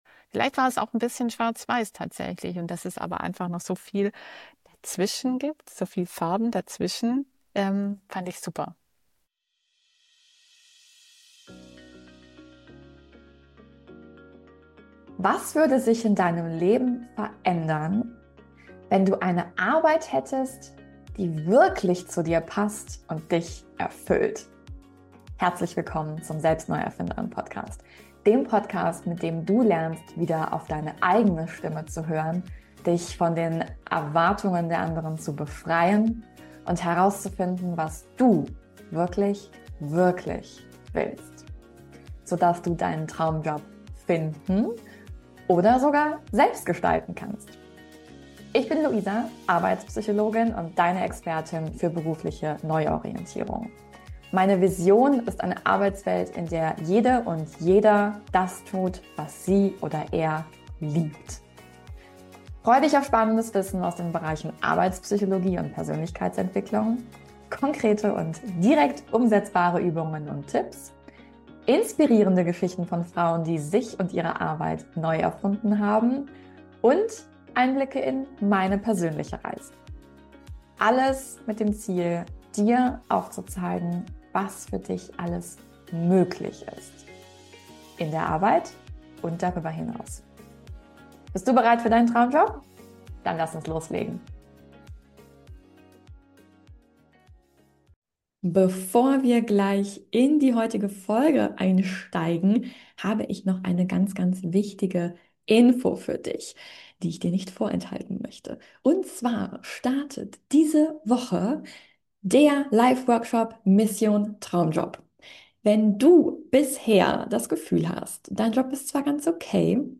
Erfolgsinterview